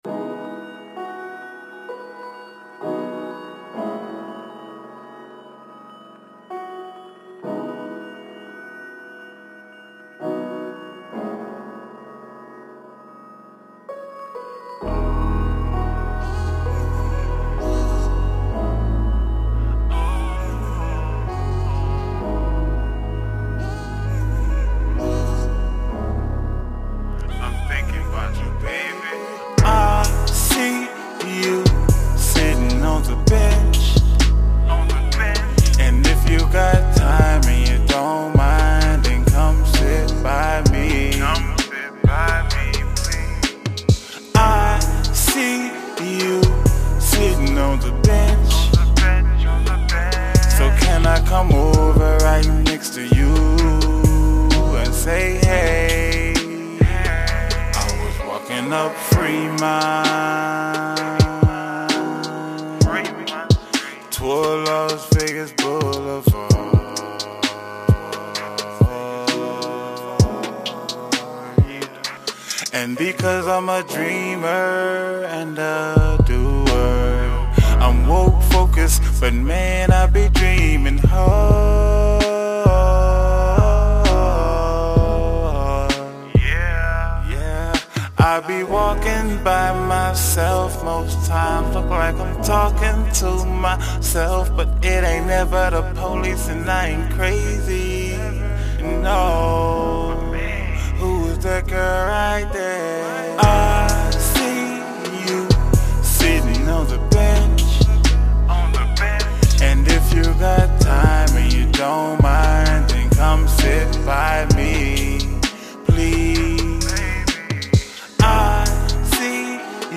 RnB, Soul
calm , relaxing , RnB music , slow-tempo , Soul music